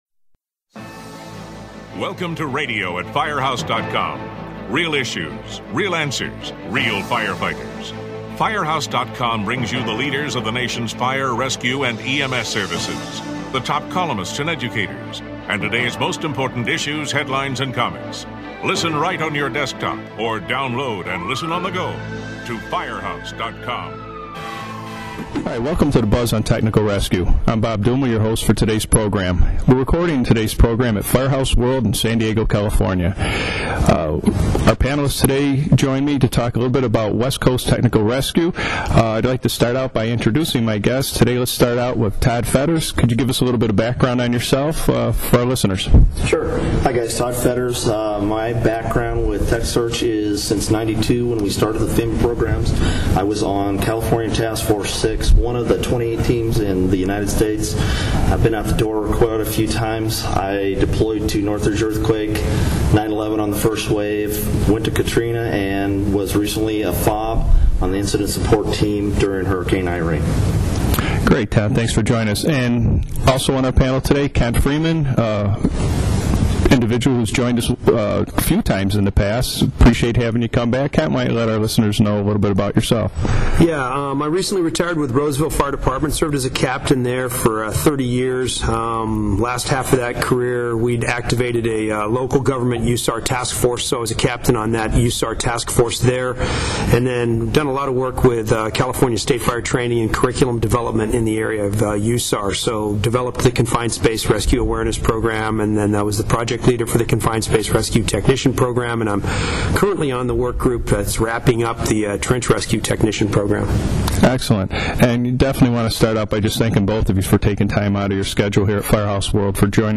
Three veteran rescue specialists review technology for on technical rescue emergencies.